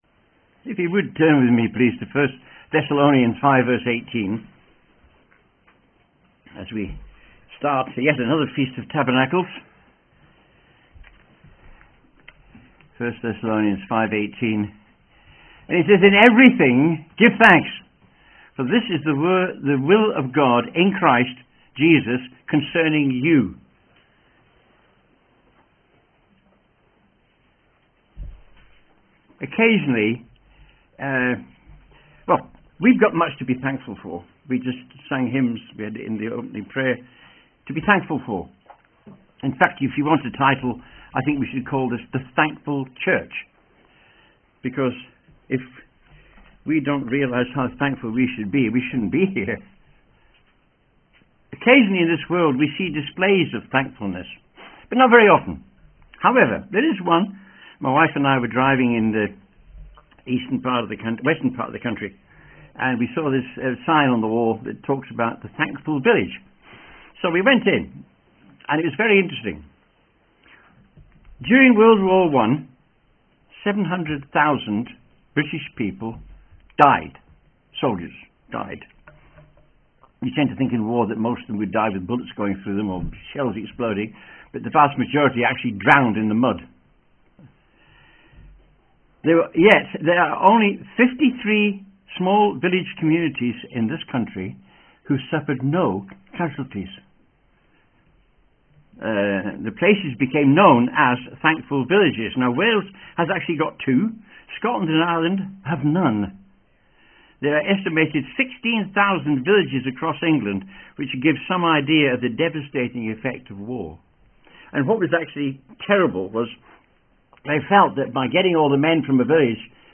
Sermonette